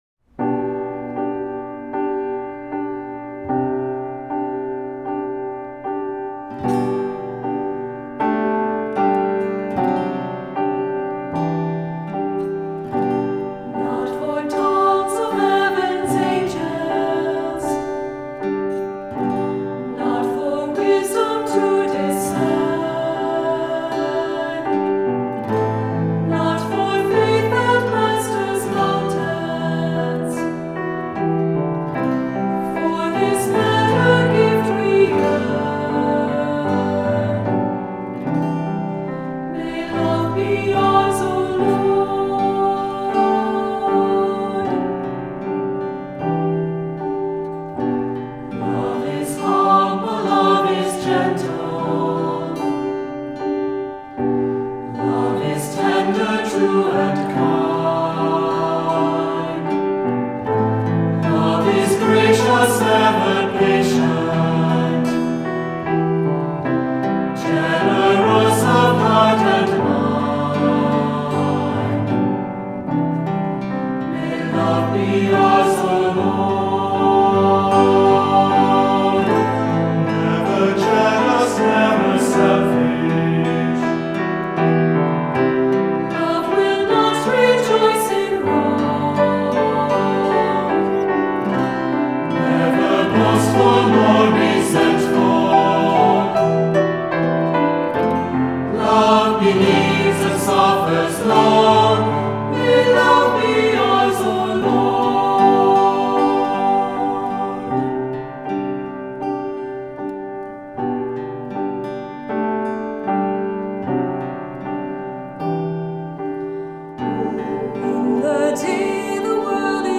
Voicing: "SATB, Soloists"